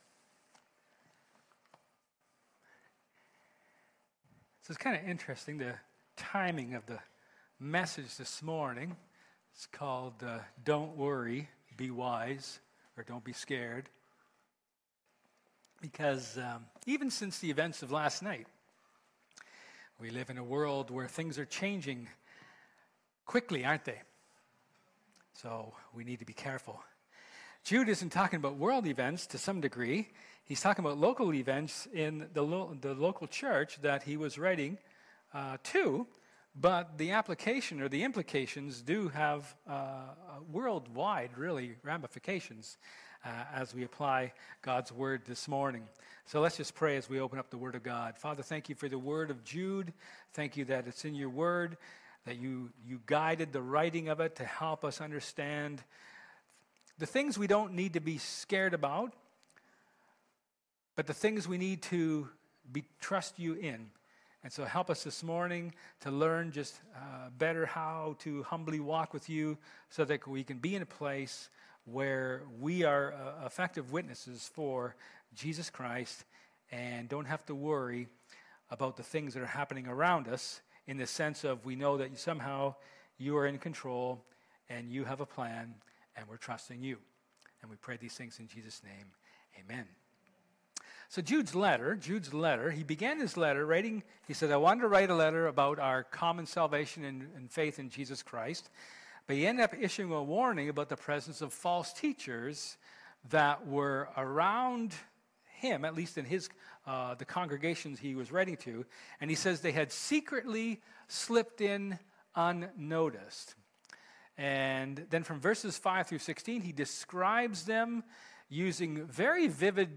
John 4:27-42 Service Type: Sermon